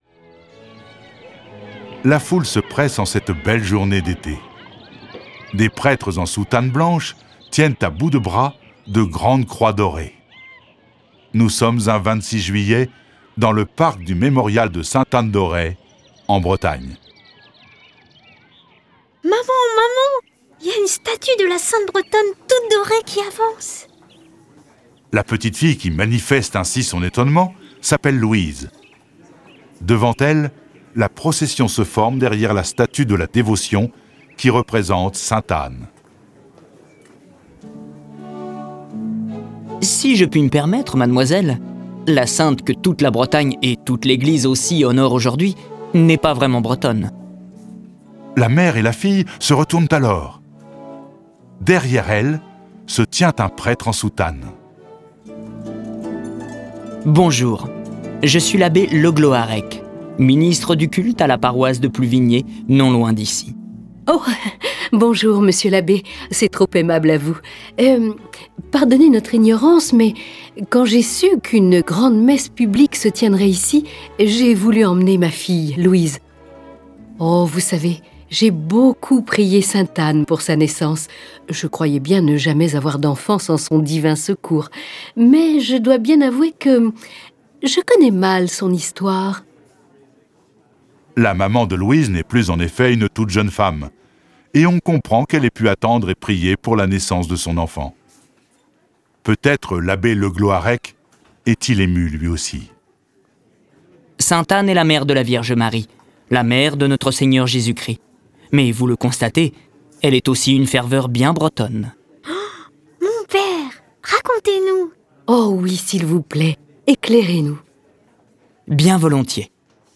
Vous découvrirez également l'origine de la renaissance du culte de Sainte-Anne d'Auray depuis 1625 suite à l'apparition d'Anne à Yvon Nicolazic, un paysan breton. Cette version sonore est animée par dix voix et accompagnée de près de quarante morceaux de musique classique.
Le récit et les dialogues sont illustrés avec les musiques de Bach, Corelli, Debussy, Delibes, Dvorak, Haendel, Haydn, Mendelssohn, Mozart, Pergolèse, Schubert, Tchaikovski, Telemann et Vivaldi.